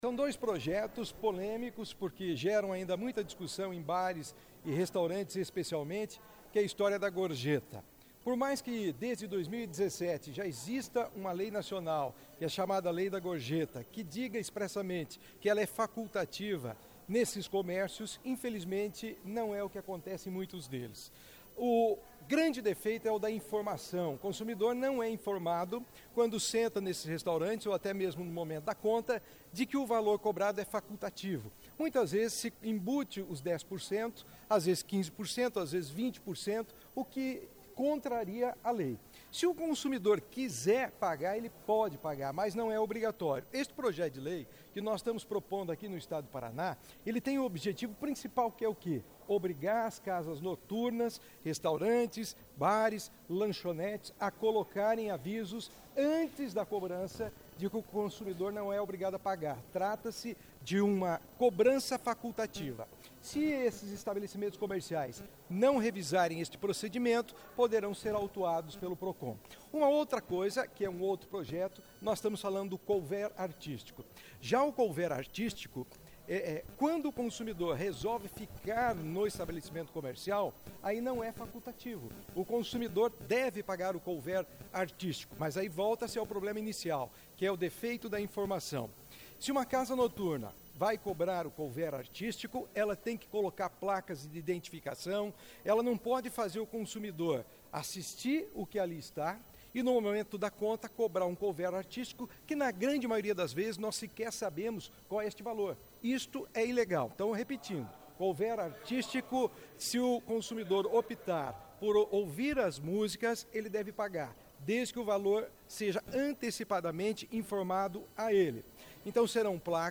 SONORA PAULO GOMES - PP